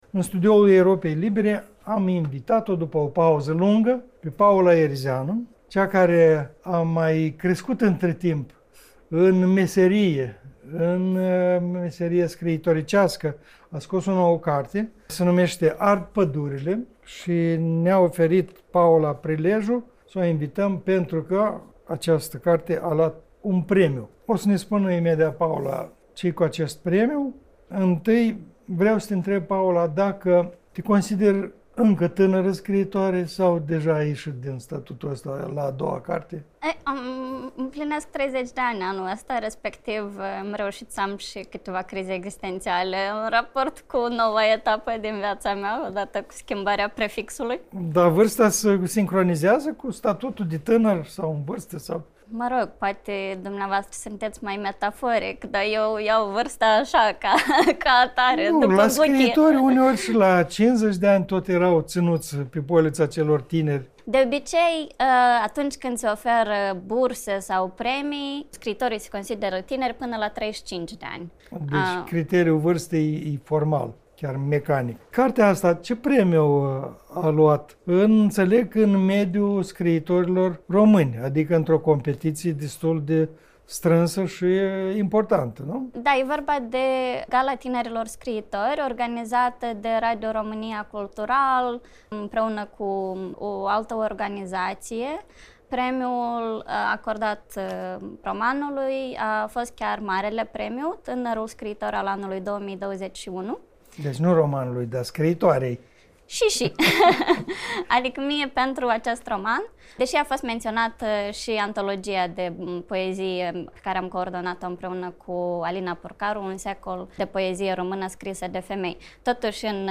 la radio Europa Liberă.